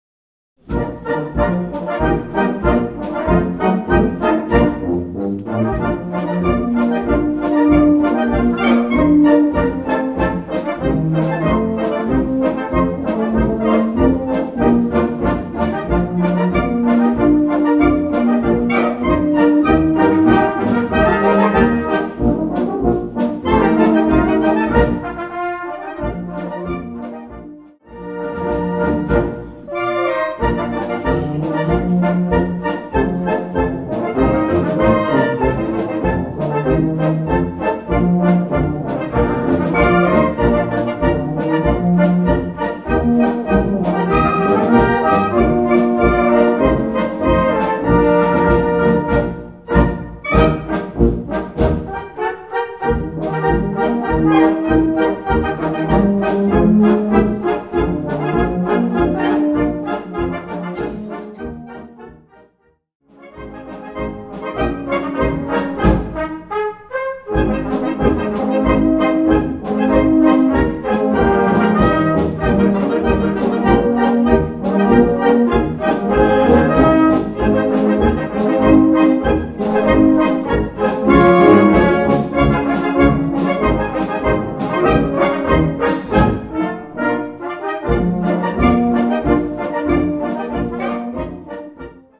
sehr graziöse und typische Polka francaise.